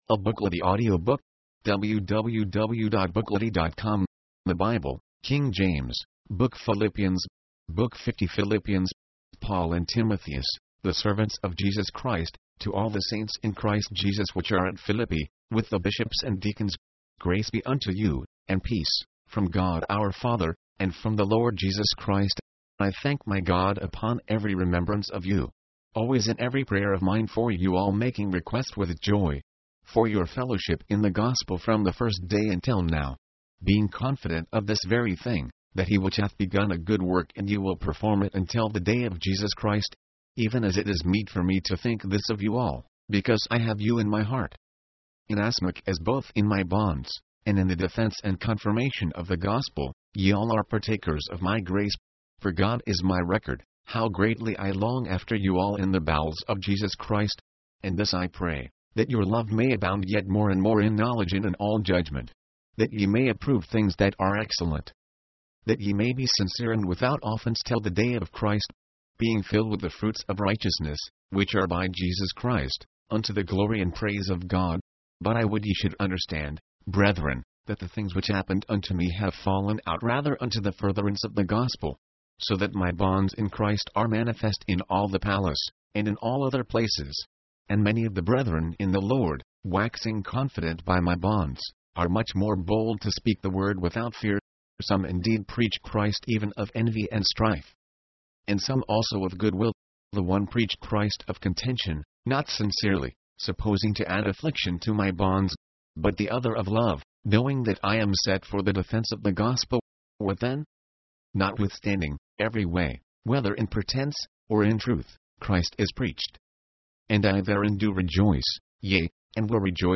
Paul exhorts Christians to press on in hope of the resurrection. mp3, audiobook, audio, book Date Added: Dec/31/1969 Rating: Add your review